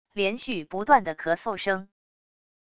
连续不断的咳嗽声wav音频